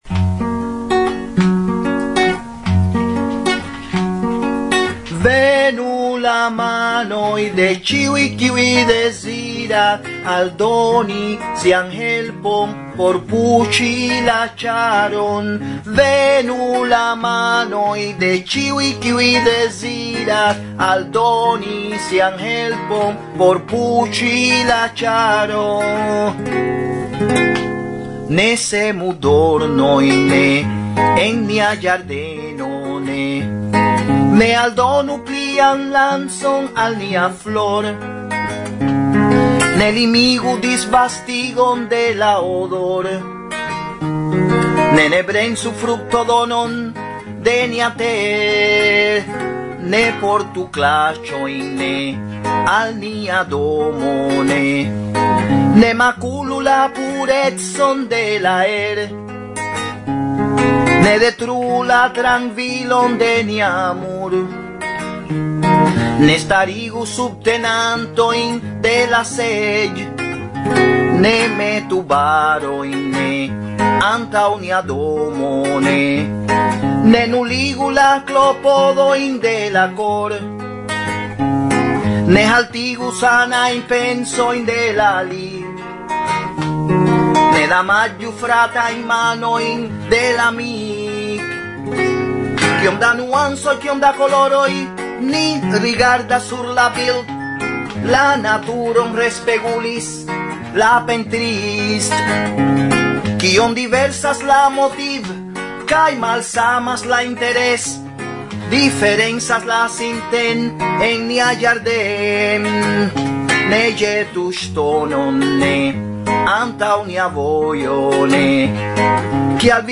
Fragmentoj de la neoficiala koncerto
SES 2011, Nitra – Slovakio